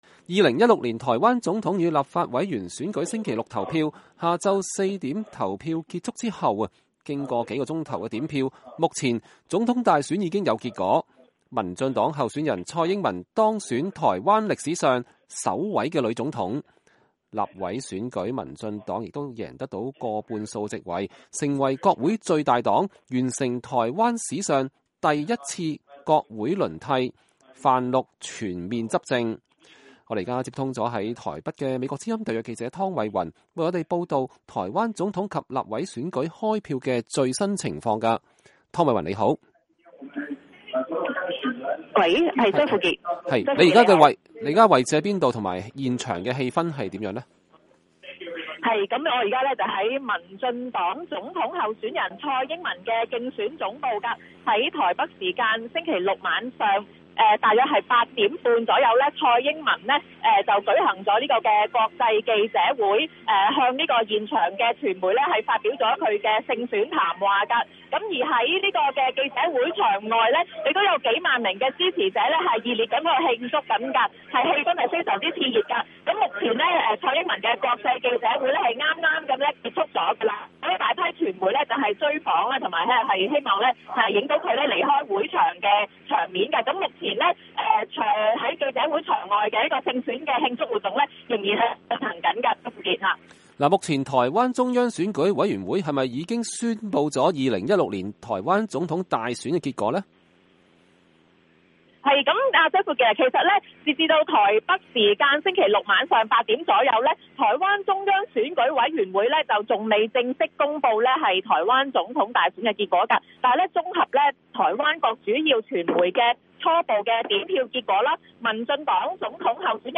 台灣總統大選現場連線 民進黨蔡英文當選首位女總統